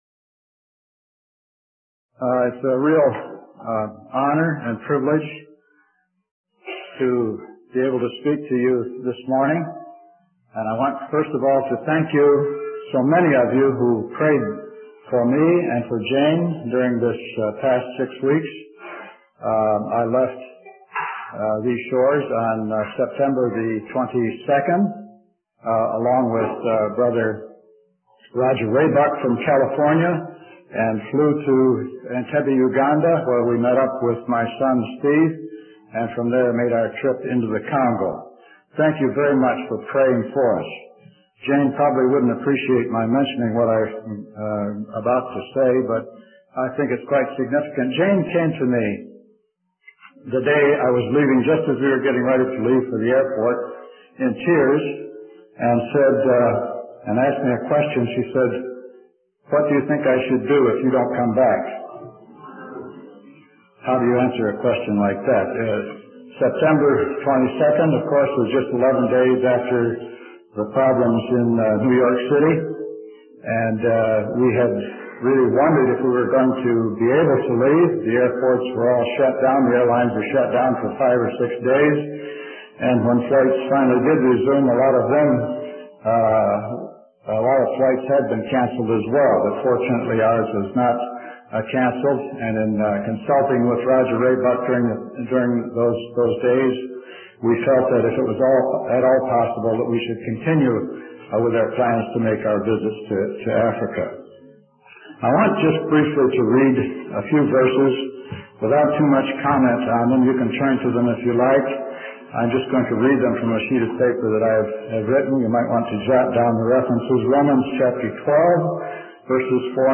In this sermon, the speaker discusses their recent trip to Africa and the challenges they faced due to the shutdown of airlines.